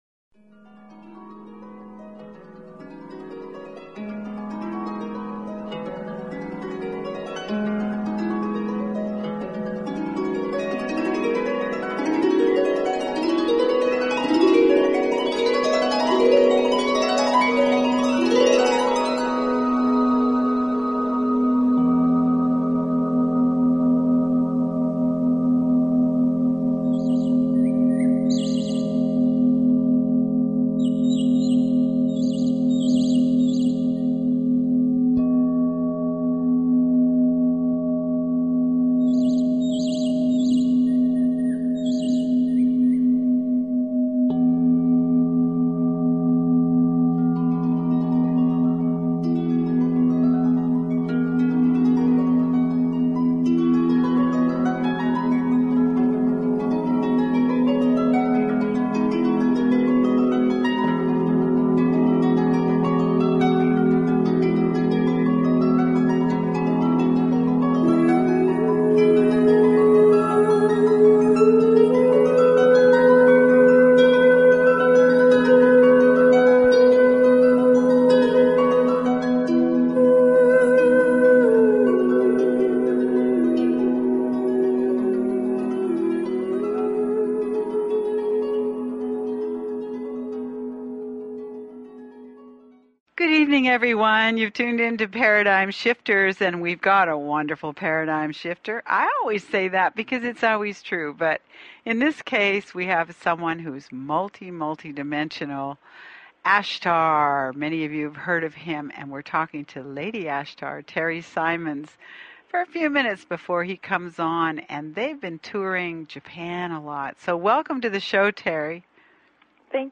Talk Show Episode, Audio Podcast, Paradigm_Shifters and Courtesy of BBS Radio on , show guests , about , categorized as